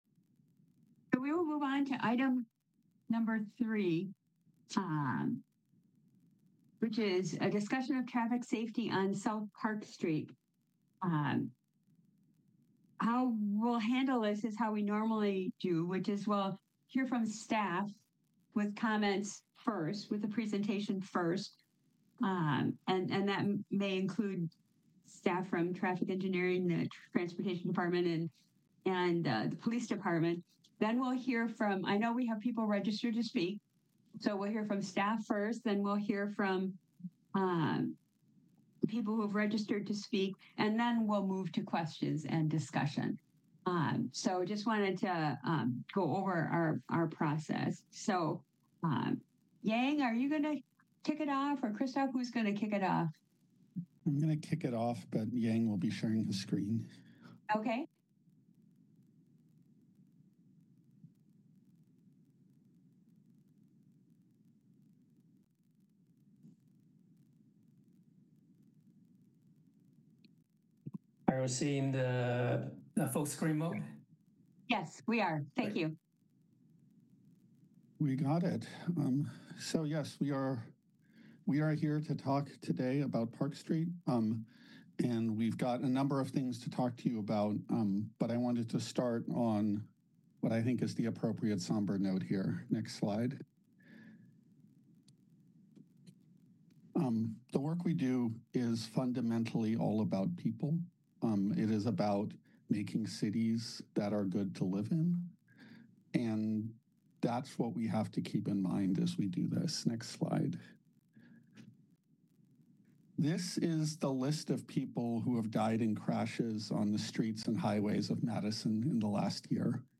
Speeches, Ceremonies, and Panel Discussions of Civic Interest.